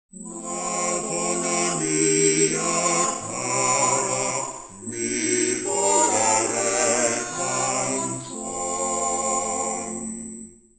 Play Quartet with aliasing
Aliased (every second sample removed without filtering)